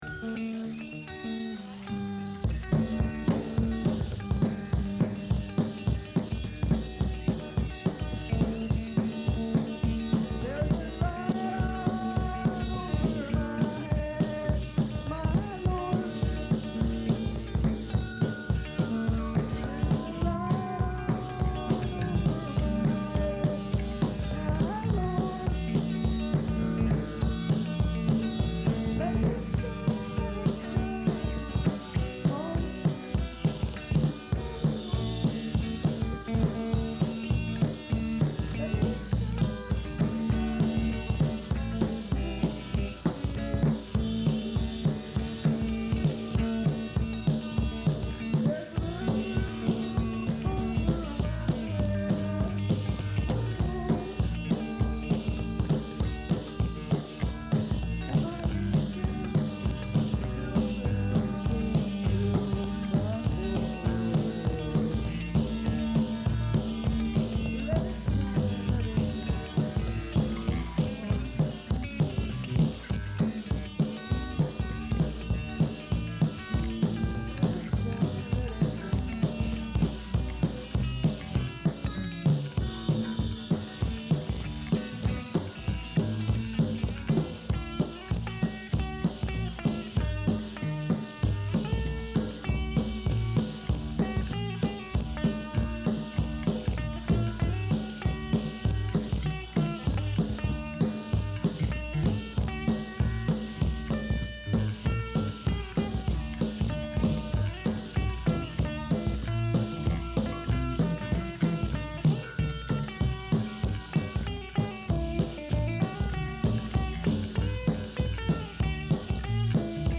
Quality is pretty damn good, considering it's from 1982.